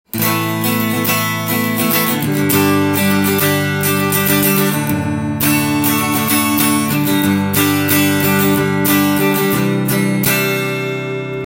フロントピックアップで弾くと低音の感じがよくわかりました。
重い木材を使うとこんなに音が引き締まった感じがするんですね！